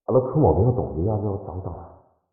三楼/囚室/肉铺配音偷听效果处理